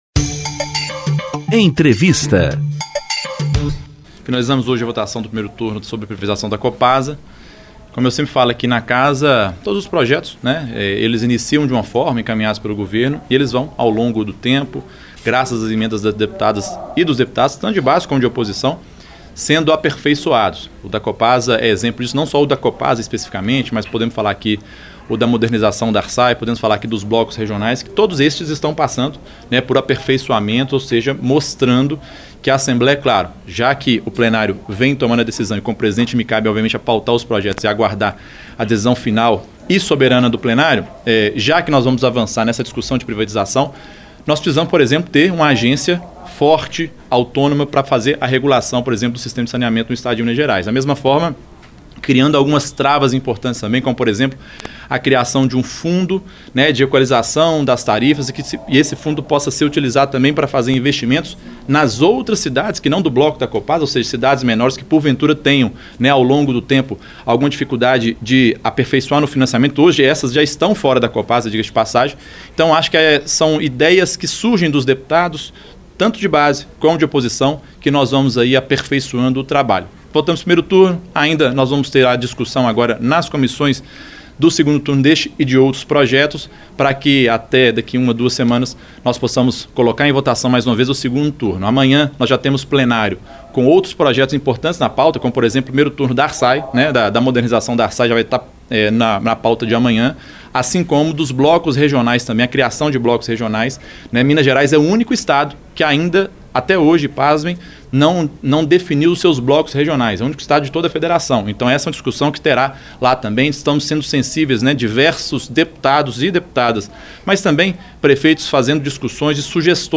Na entrevista coletiva, o presidente da ALMG afirmou que o projeto de lei deverá ser aperfeiçoado, com propostas de alterações, como um fundo para amortização de tarifas.